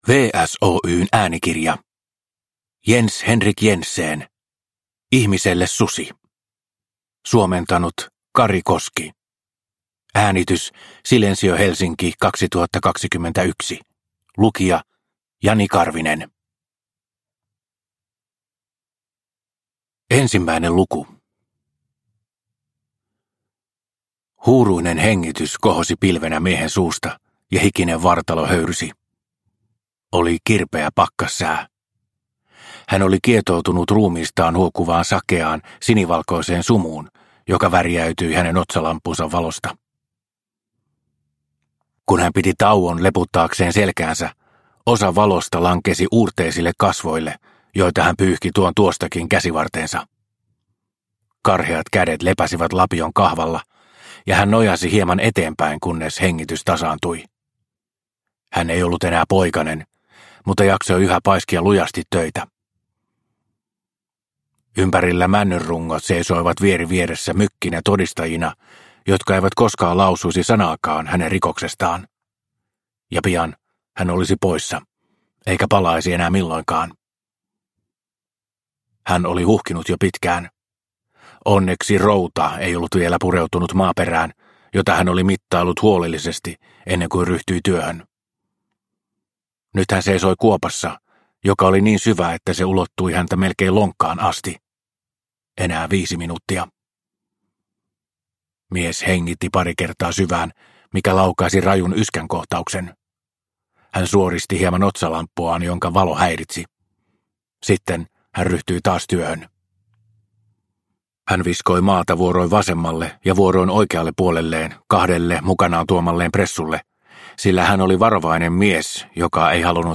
Ihmiselle susi – Ljudbok – Laddas ner